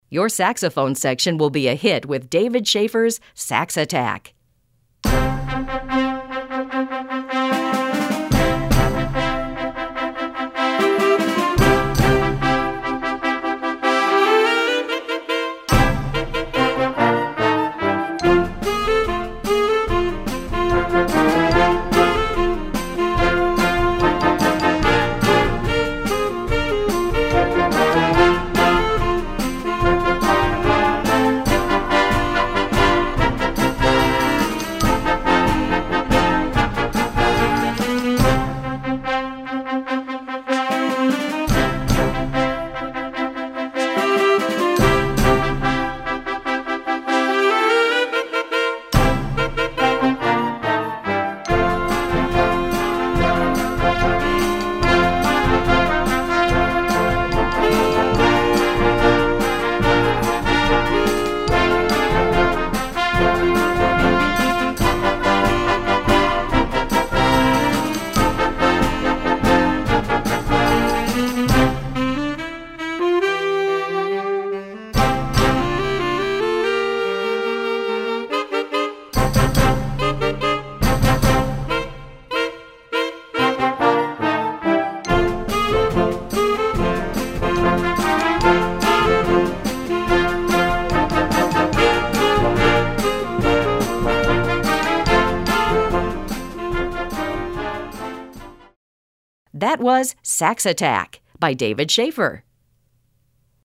Voicing: Saxophone Section w/ Band